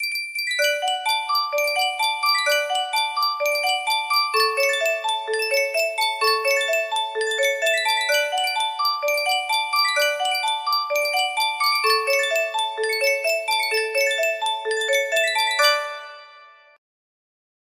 Yunsheng Caja de Musica - La Bomba 5491 music box melody
Full range 60